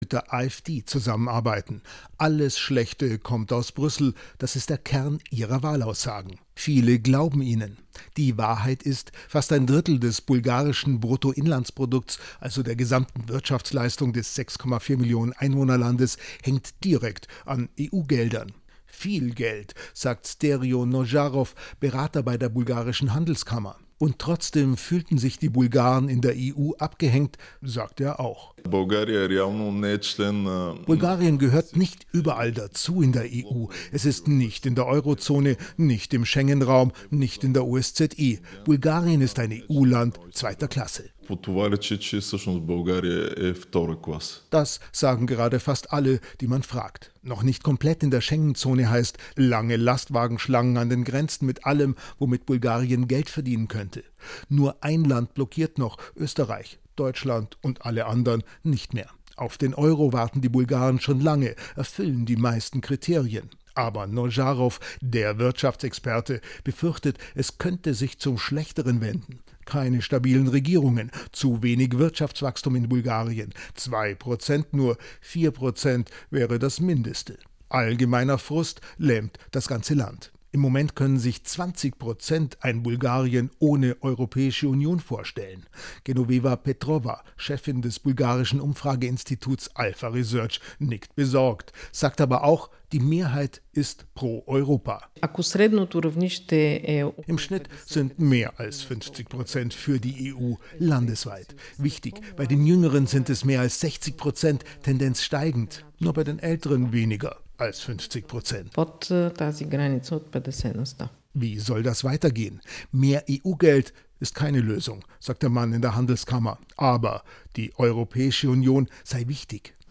ARD С РЕПОРТАЖ ЗА ГОТОВНОСТТА НА БЪЛГАРИЯ ЗА ШЕНГЕН